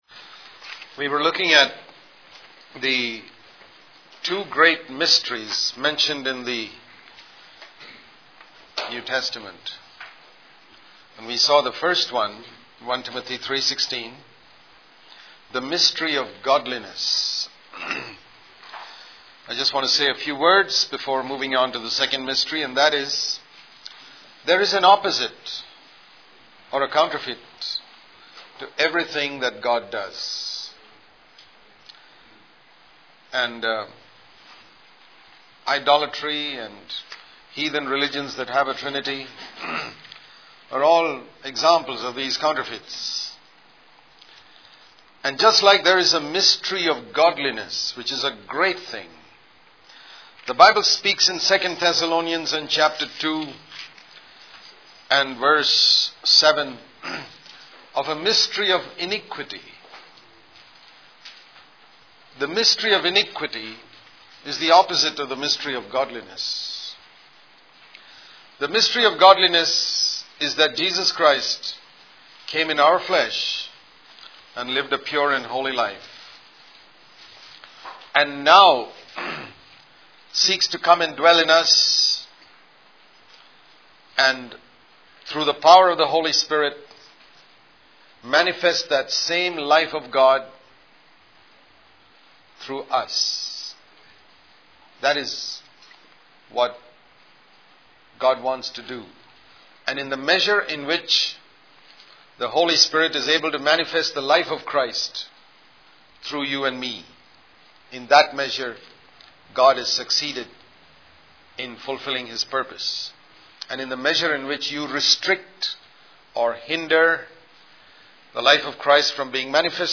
In this sermon, the speaker emphasizes the importance of understanding Jesus' purpose on earth and how it relates to the calling of the church. He highlights the significance of money and how it should not be a focus for church leaders.